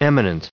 269_eminent.ogg